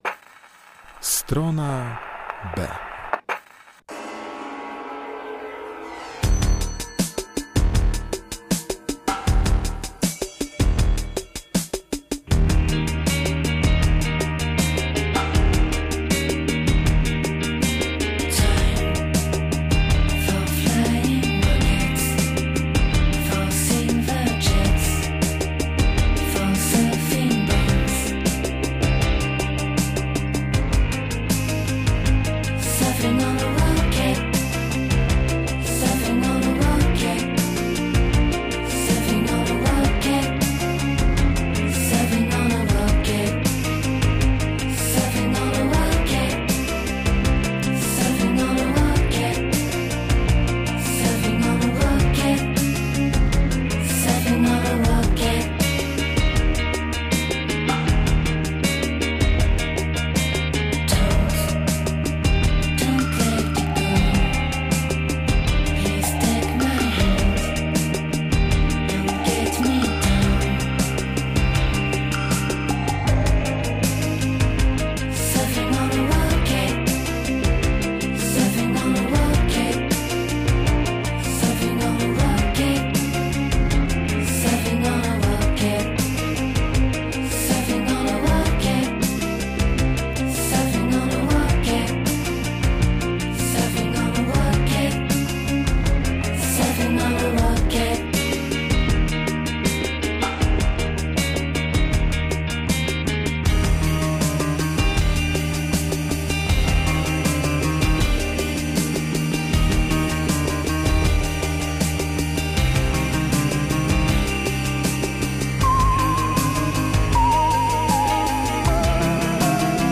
STRONA B - godzina z elektroniką, ambient, post punkiem i shoegaze.